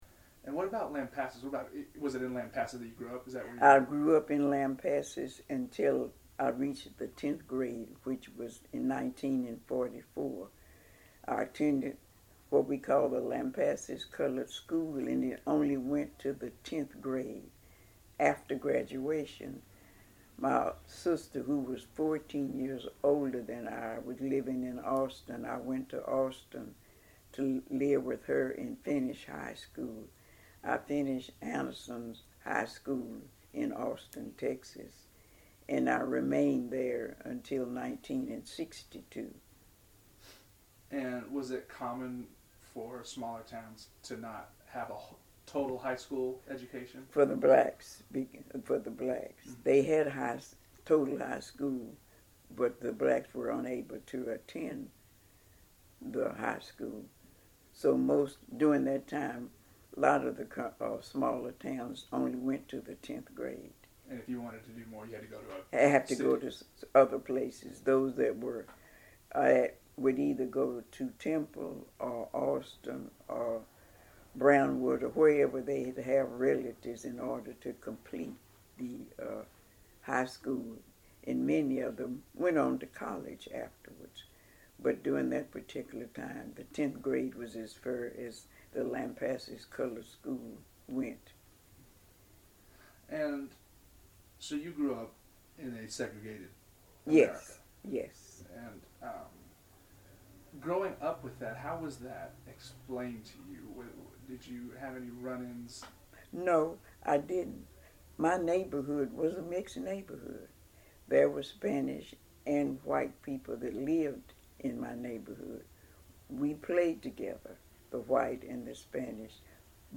Oral History Interview